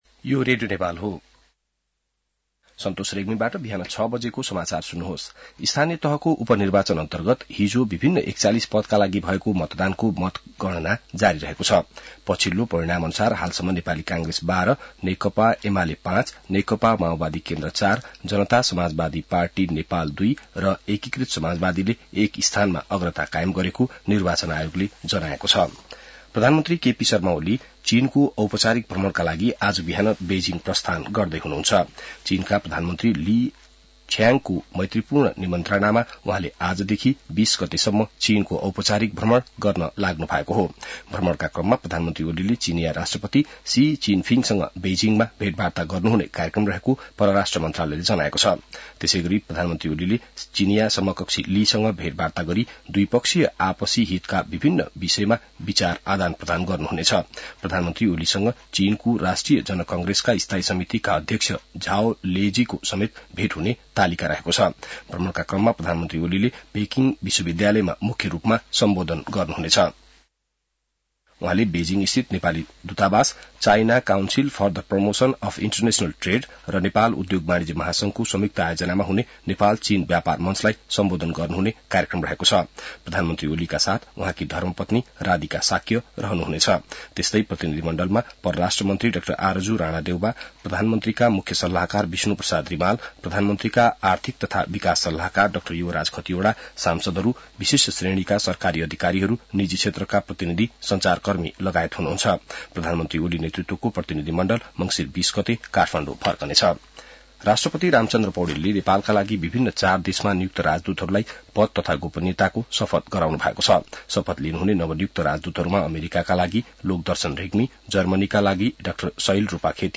बिहान ६ बजेको नेपाली समाचार : १८ मंसिर , २०८१